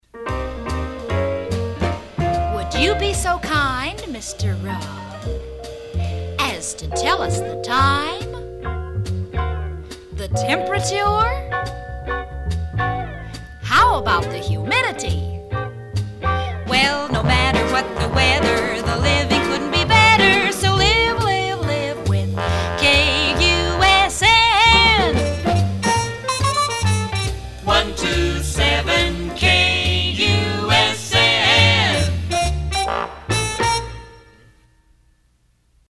NOTE: These jingle samples are from my private collection.